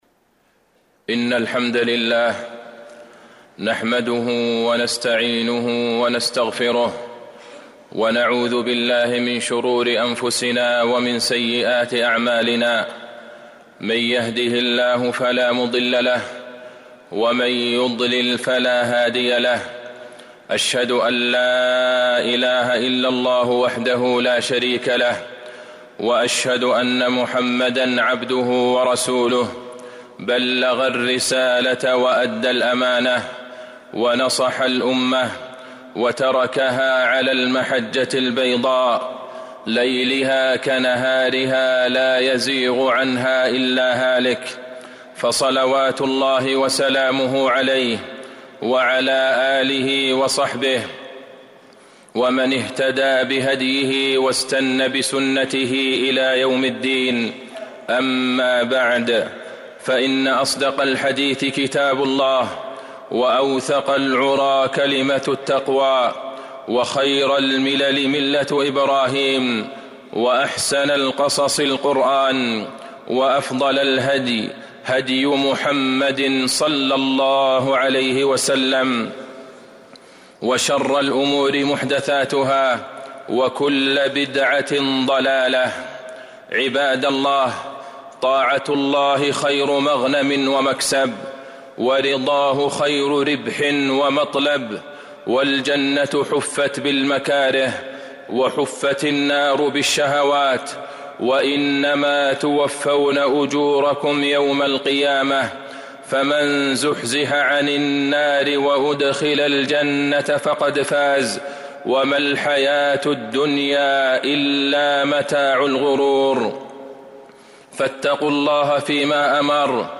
المدينة: فريضة الحج وتعلم أحكامه - عبد الله بن عبد الرحمن البعيجان (صوت - جودة عالية